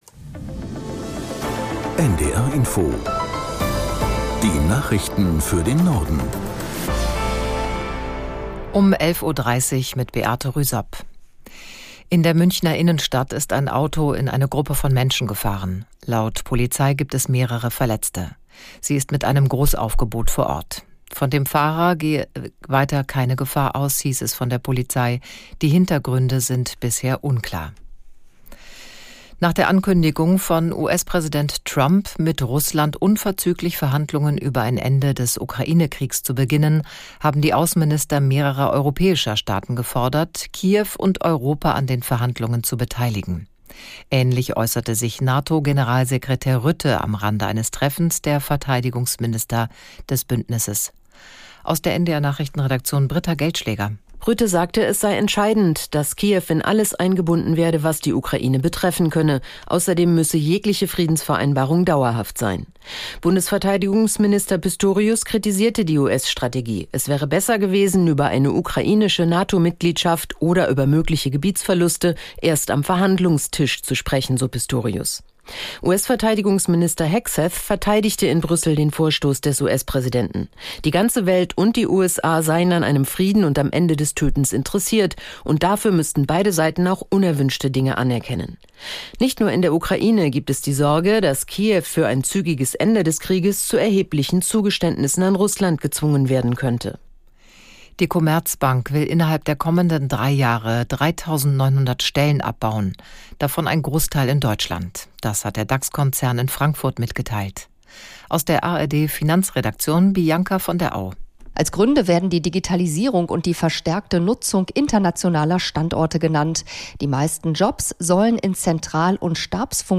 Nachrichten - 13.02.2025